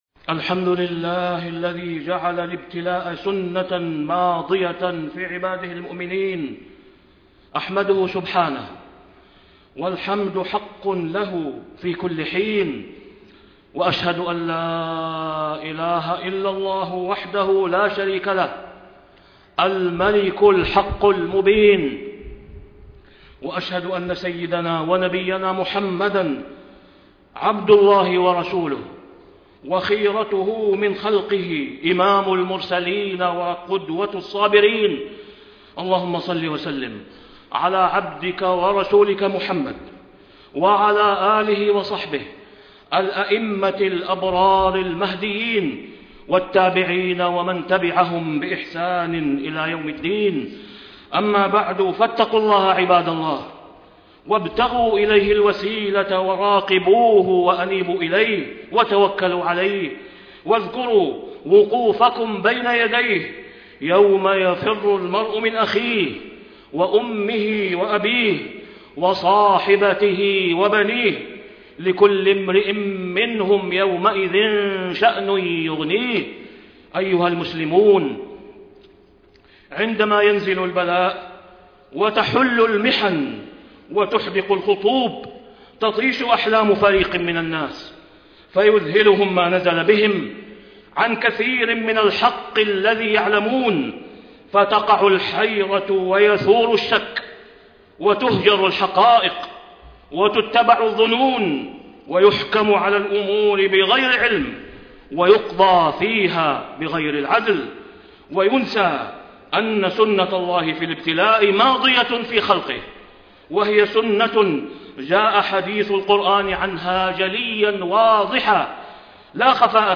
تاريخ النشر ١٣ جمادى الأولى ١٤٣٥ هـ المكان: المسجد الحرام الشيخ: فضيلة الشيخ د. أسامة بن عبدالله خياط فضيلة الشيخ د. أسامة بن عبدالله خياط حكم الابتلاء